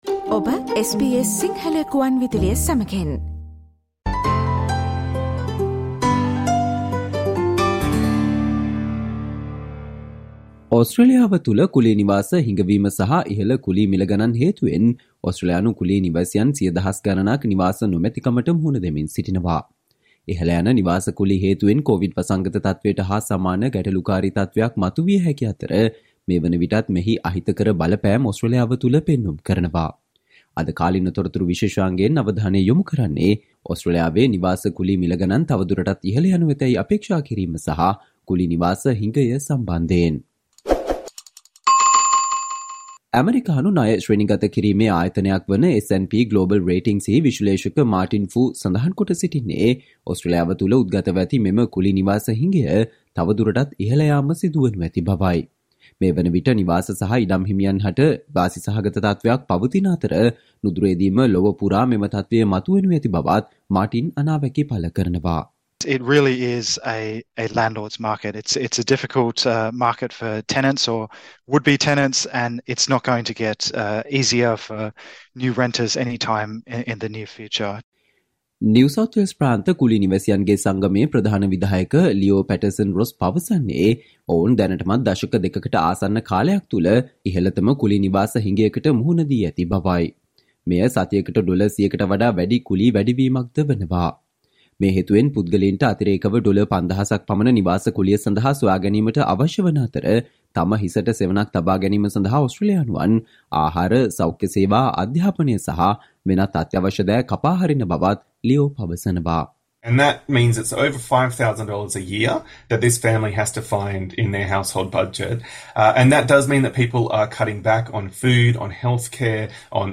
Today - 06 March, SBS Sinhala Radio current Affair Feature on Australia's rental housing problem will soon worsen due to high migration and rising rental prices